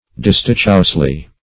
distichously - definition of distichously - synonyms, pronunciation, spelling from Free Dictionary Search Result for " distichously" : The Collaborative International Dictionary of English v.0.48: Distichously \Dis"tich*ous*ly\, adv.